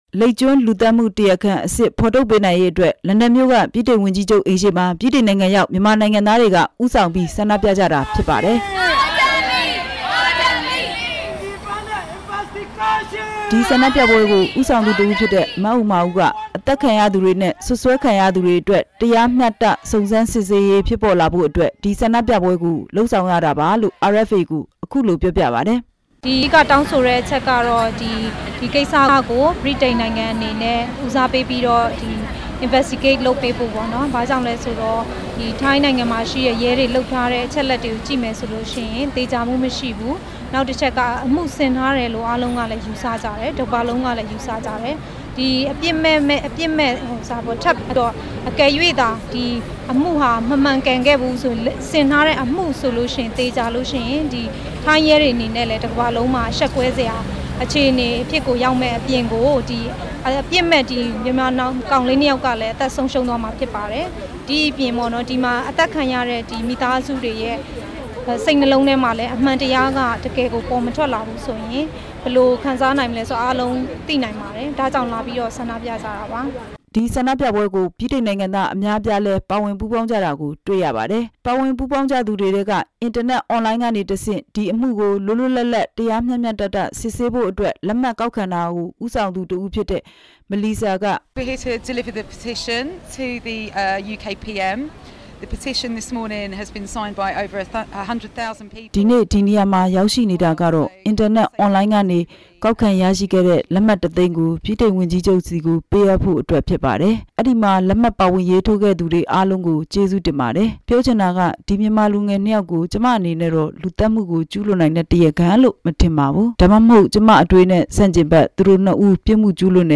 လန်ဒန်က သတင်းပေးပို့ချက်